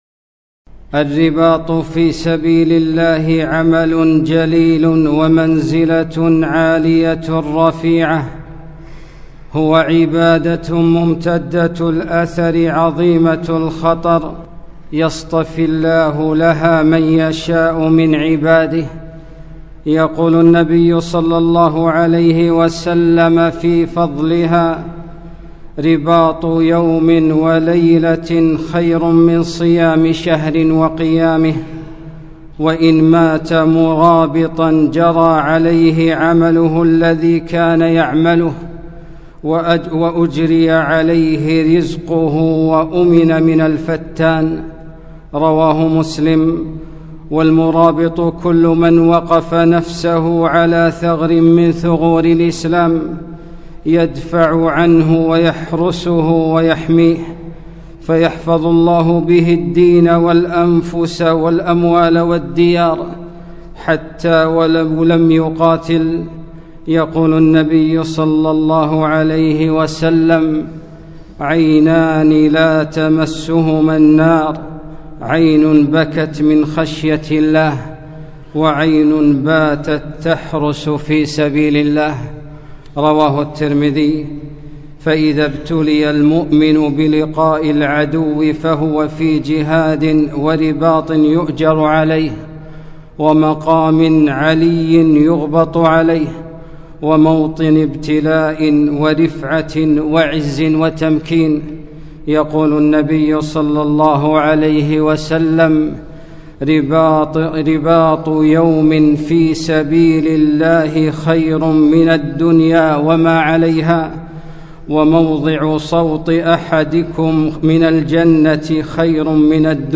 خطبة - المرابطون